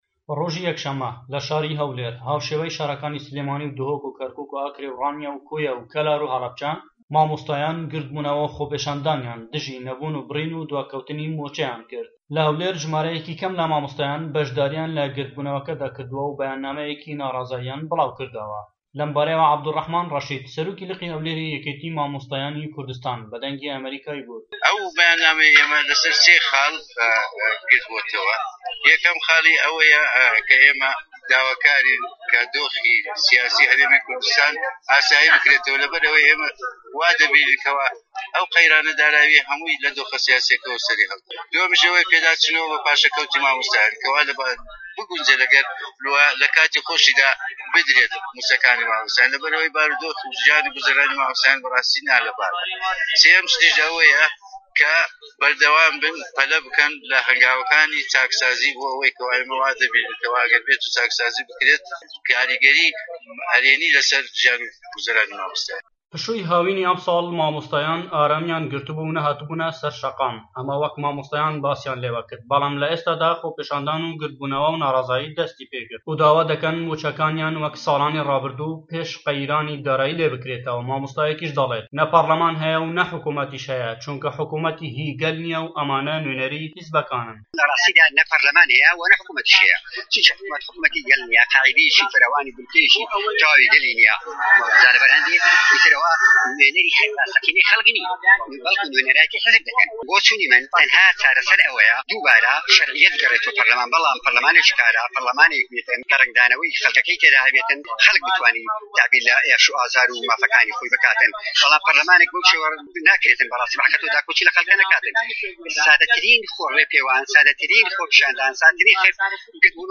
دەقی ڕاپۆرتی پەیامنێرمان